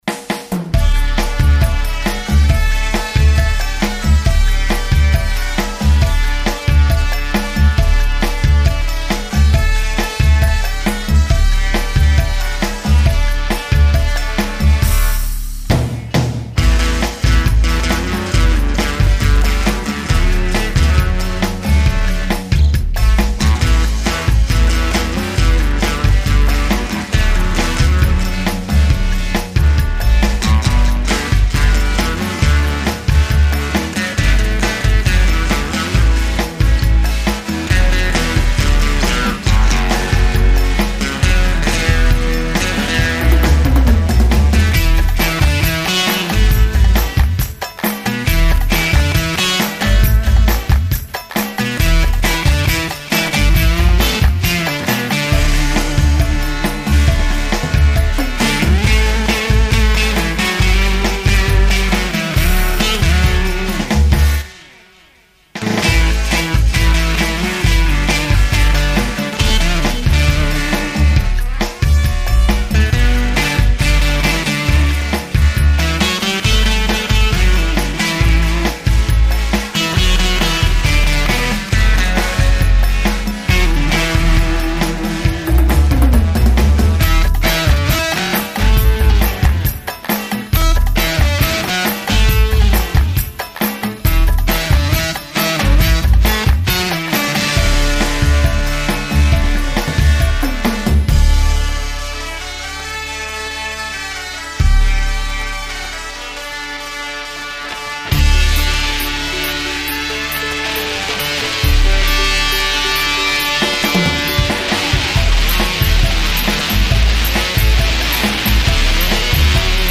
(cover version)